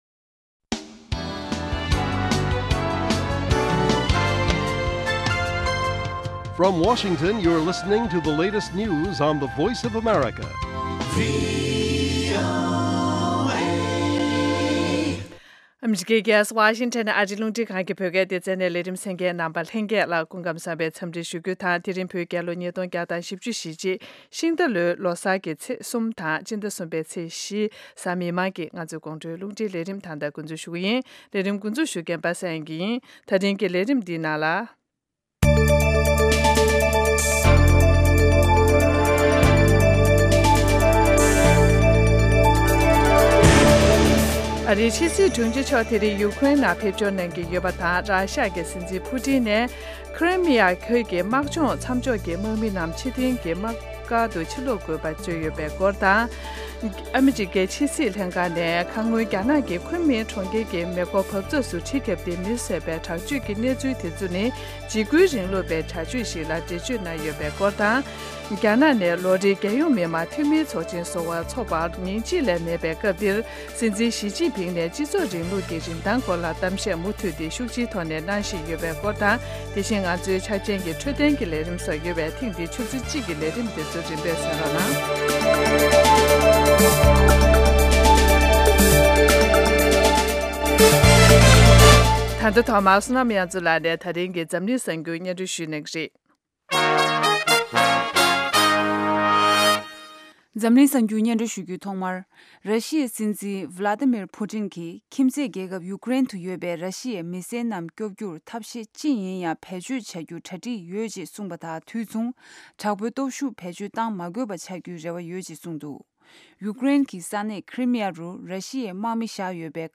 དགོང་དྲོའི་གསར་འགྱུར ཉིན་ལྟར་ཐོན་བཞིན་པའི་བོད་དང་ཨ་རིའི་གསར་འགྱུར་ཁག་དང་། འཛམ་གླིང་གསར་འགྱུར་ཁག་རྒྱང་སྲིང་ཞུས་པ་ཕུད། དེ་མིན་དམིགས་བསལ་ལེ་ཚན་ཁག་ཅིག་རྒྱང་སྲིང་ཞུ་བཞིན་ཡོད། རྒྱང་སྲིང་དུས་ཚོད། Daily བོད་ཀྱི་དུས་ཚོད། 22:00 འཛམ་གླིང་གཅིག་གྱུར་གྱི་དུས་ཚོད། 1400 ལེ་ཚན་རིང་ཐུང་། 60 གསན་ན། MP༣ Podcast